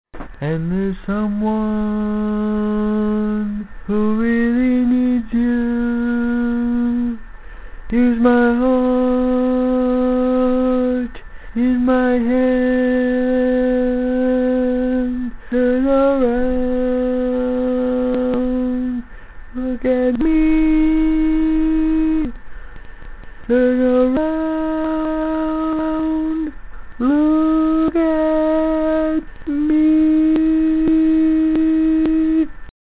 Key written in: C Major
Each recording below is single part only.